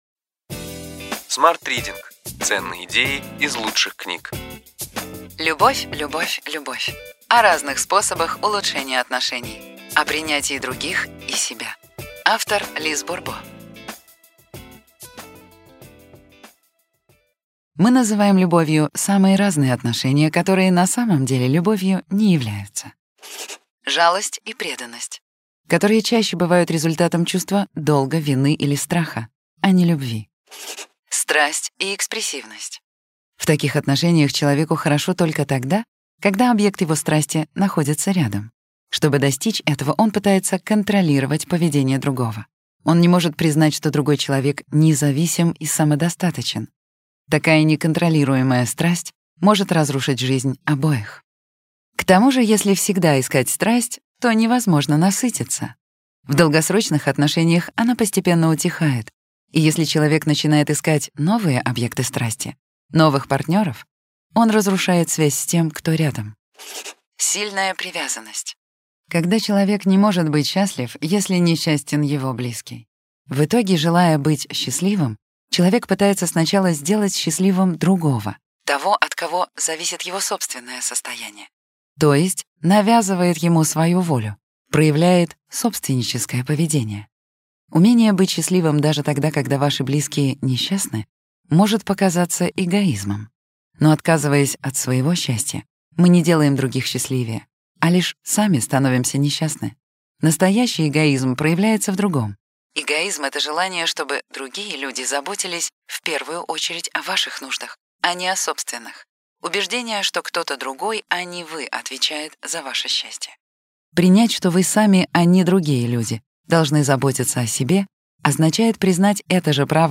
Аудиокнига Ключевые идеи книги: Любовь, любовь, любовь. О разных способах улучшения отношений, о приятии других и себя. Лиз Бурбо | Библиотека аудиокниг